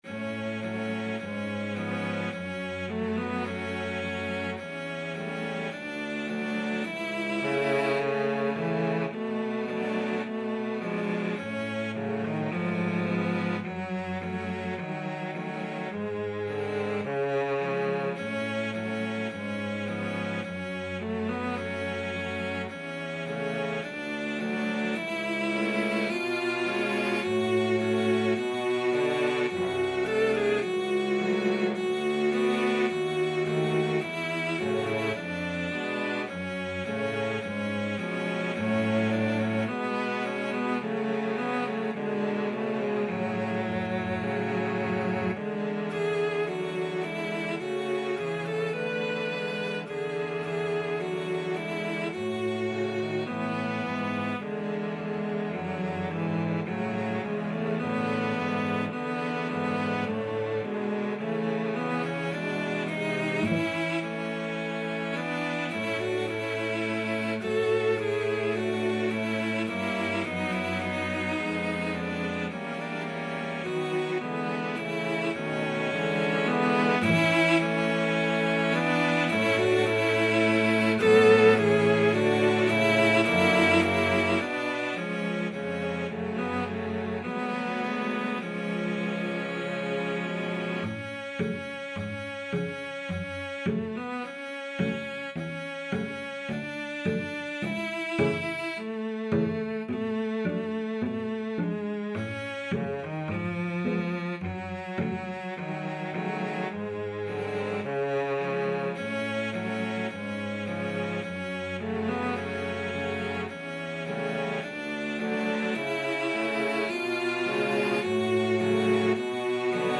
Allegro non troppo =106 (View more music marked Allegro)
4/4 (View more 4/4 Music)
Cello Quartet  (View more Intermediate Cello Quartet Music)
Classical (View more Classical Cello Quartet Music)